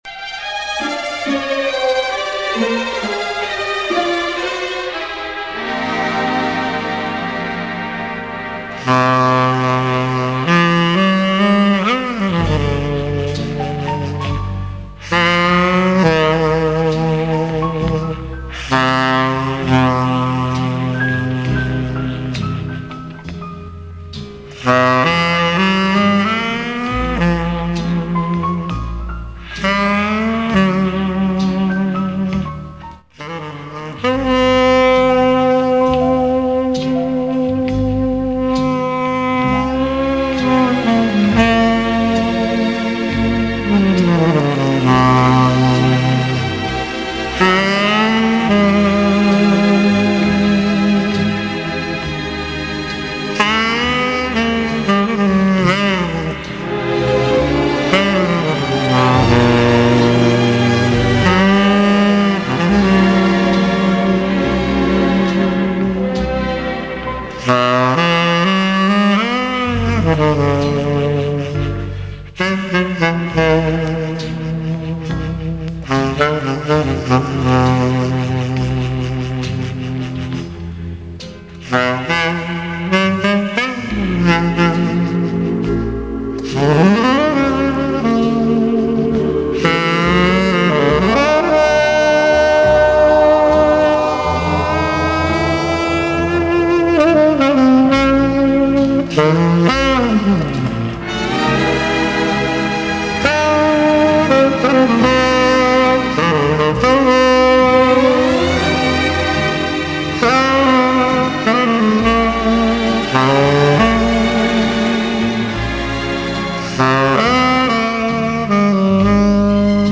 В этом произведении солирует саксофон-тенор.